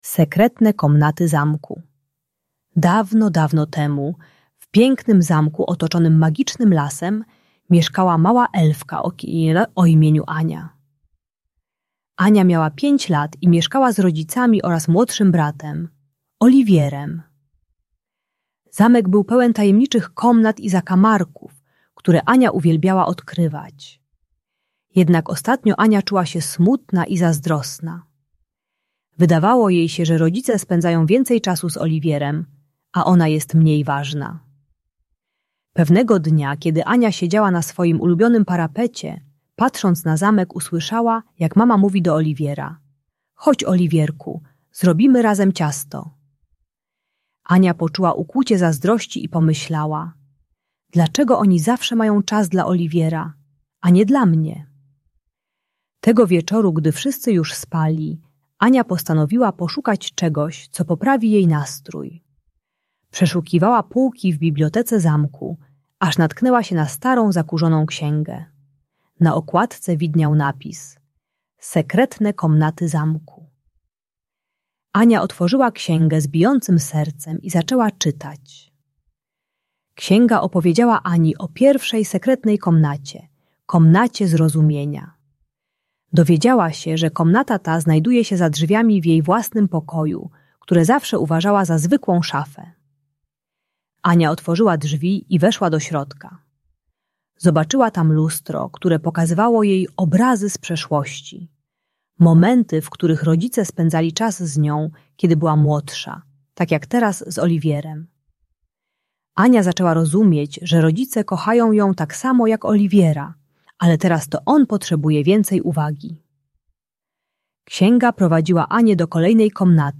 Sekretne Komnaty Zamku - Rodzeństwo | Audiobajka